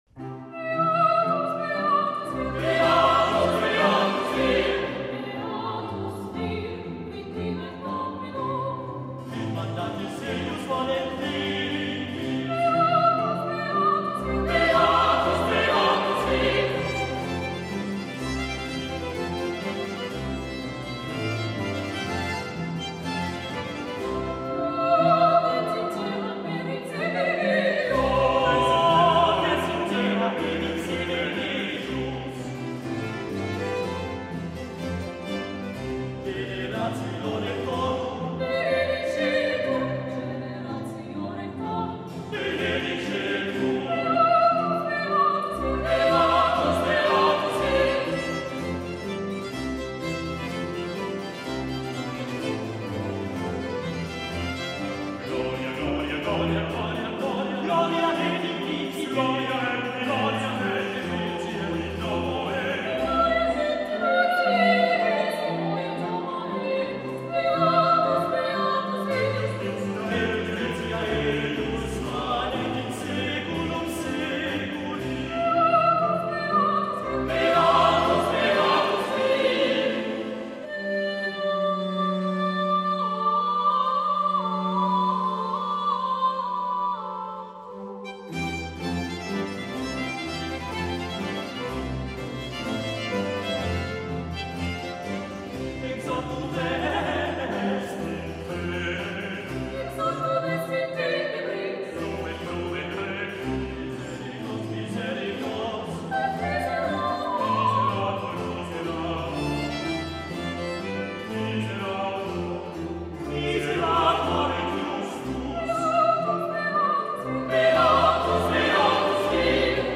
A colloquio con William Christie, profeta della Filologia